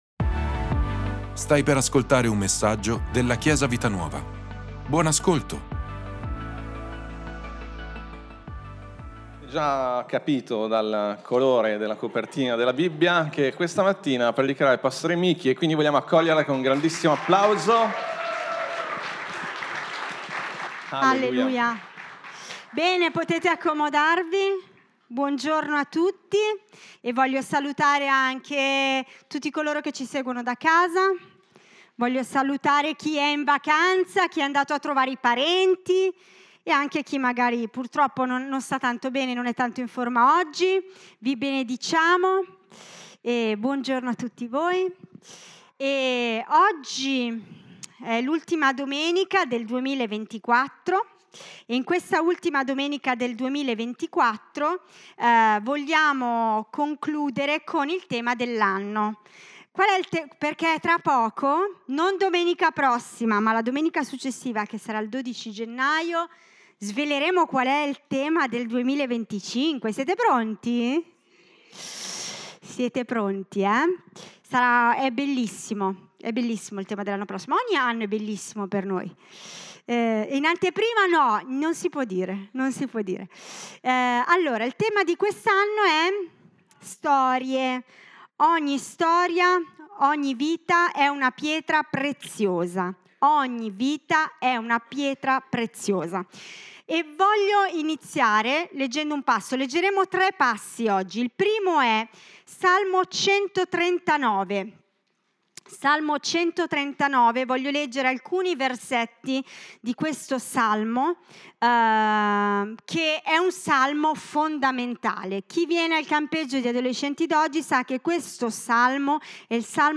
Ascolta la predicazione: La nostra storia - Chiesa Vita Nuova